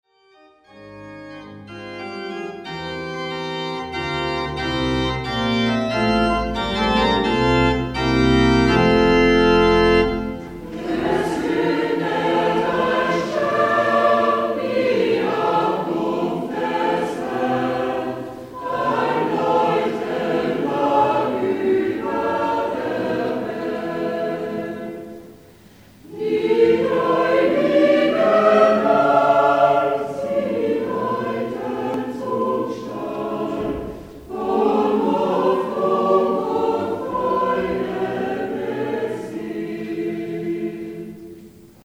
(Live-Aufnahmen)